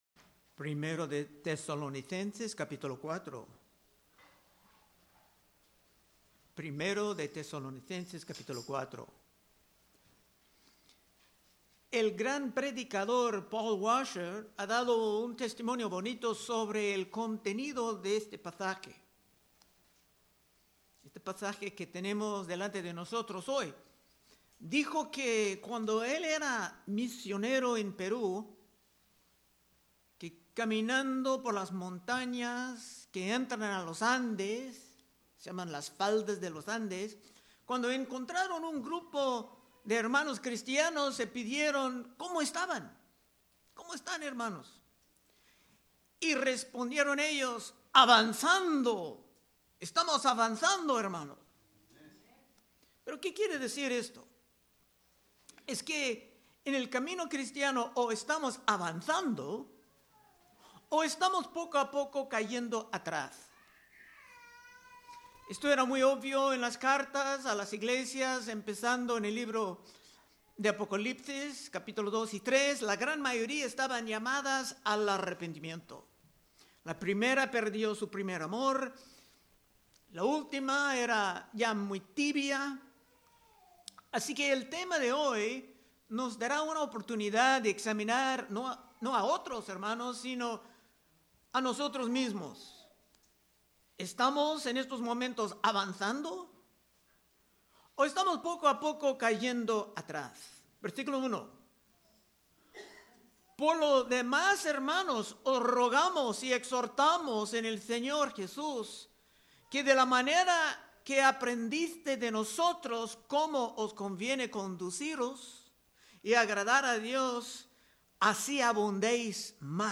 Predicaciones De Exposición Libro De 1 Tesalonicenses